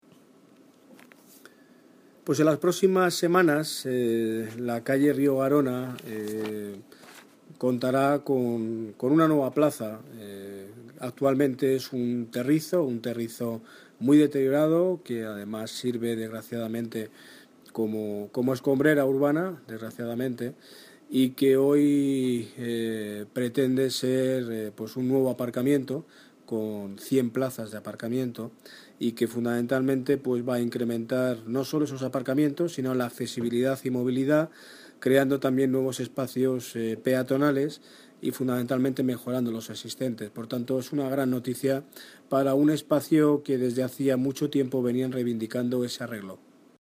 Audio - Daniel Ortiz (Alcalde de Móstoles) Sobre Rio garona